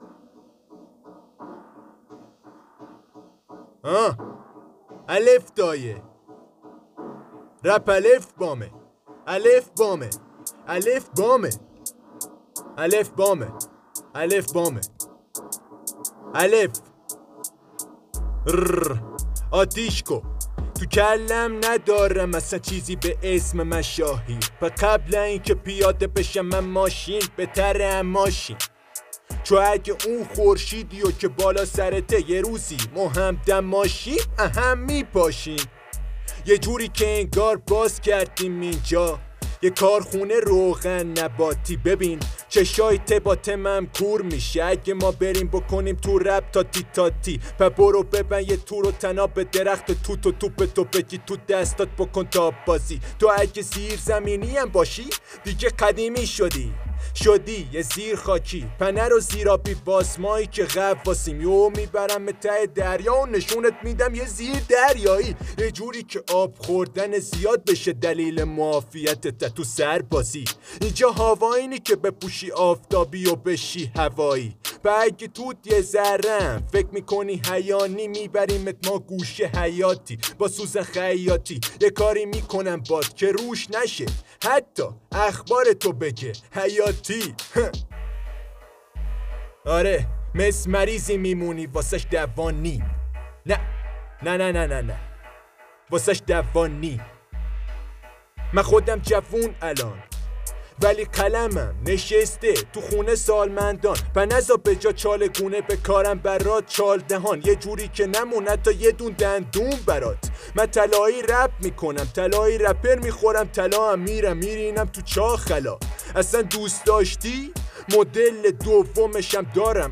Live FreeStyle